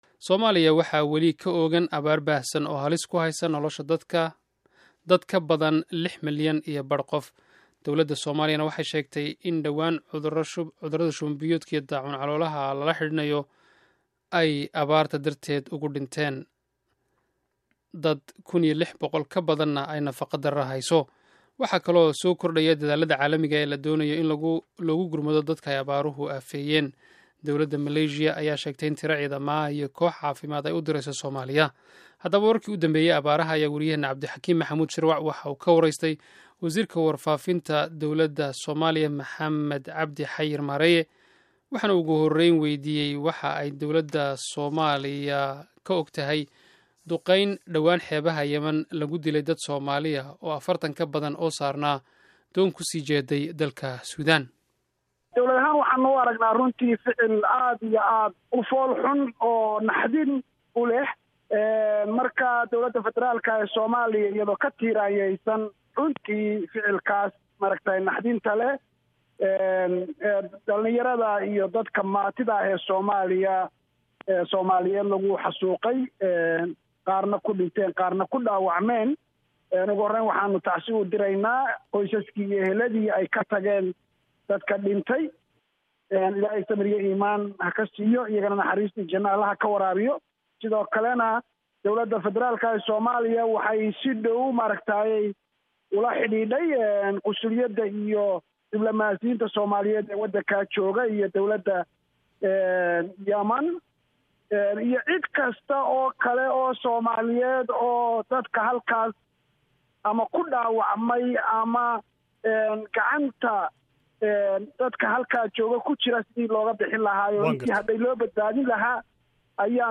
Dhageyso Warbixinta Abaaraha